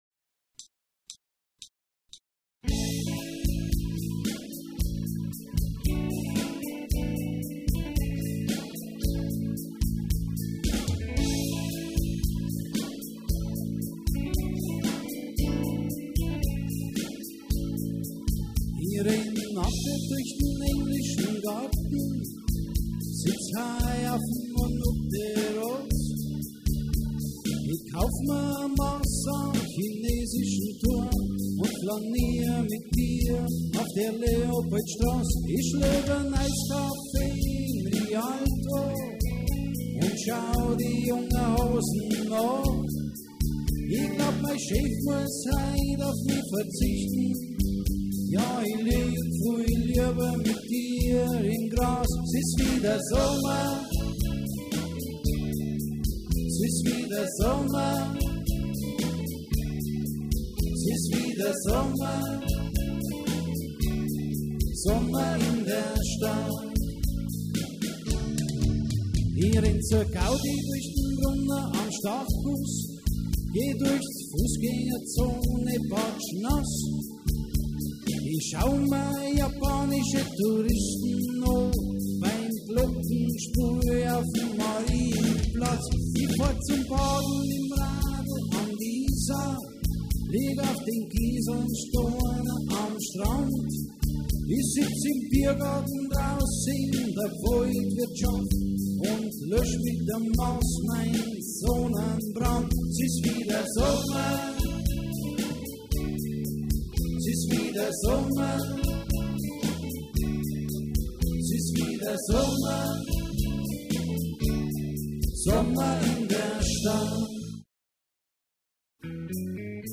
• Coverband
• Allround Partyband